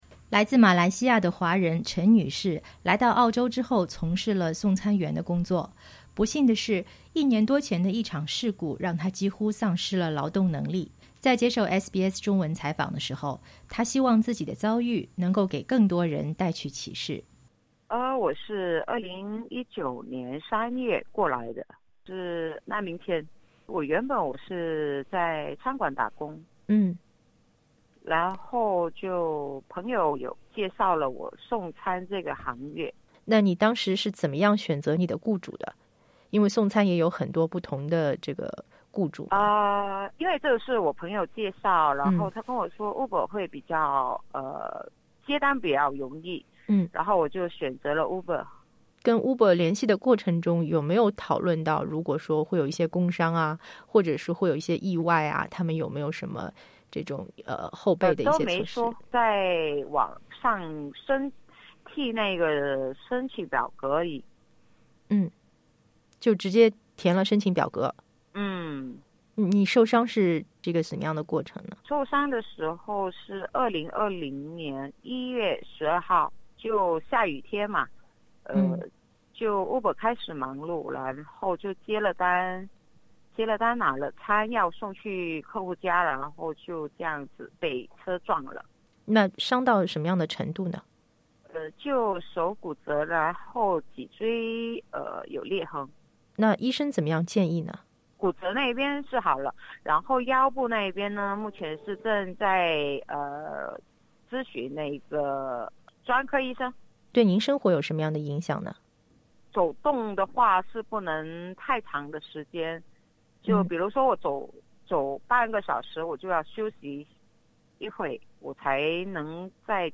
在接受SBS中文采访时，她希望自己的遭遇能给更多的人带去启示。